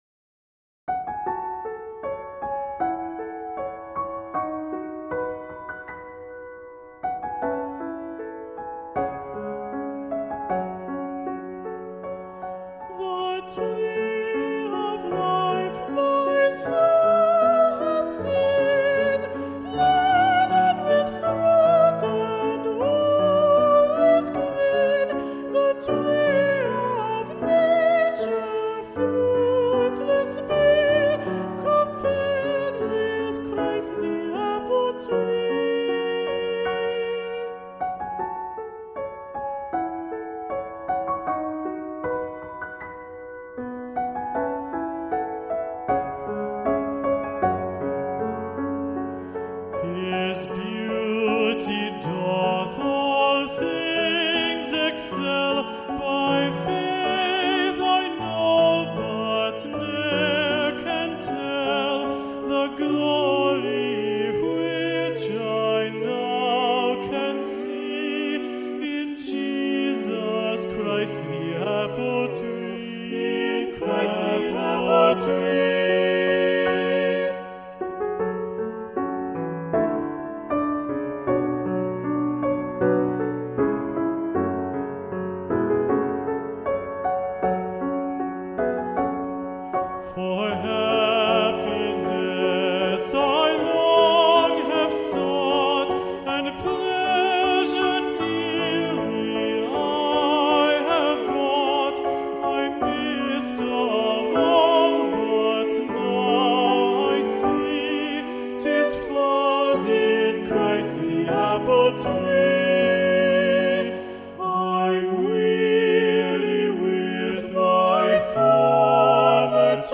Choral Music
Choral ~ Holiday ~ with Keyboard
SCORING:  SATB (some divisi), acc. by piano